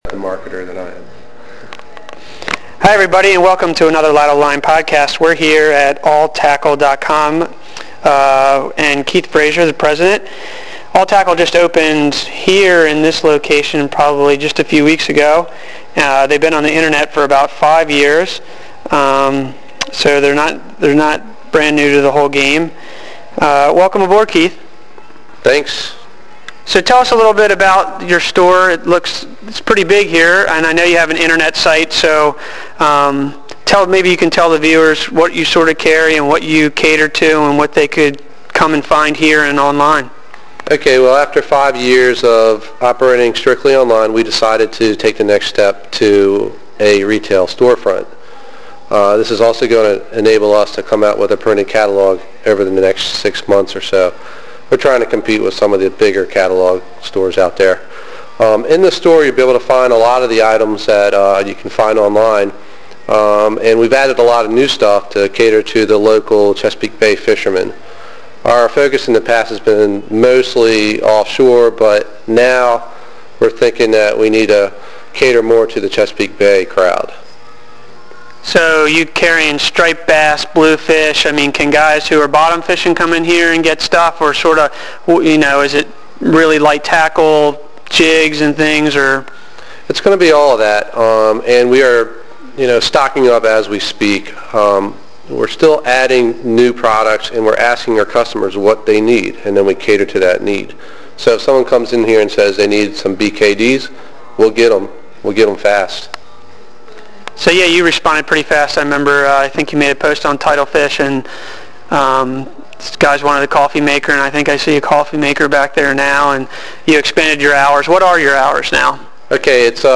I thought it would be cool to start a fishing podcast radio show to share my conversations with other anglers and do some interviews with charters, guides, fisheries scientists, fisheries managers, and other fishy people.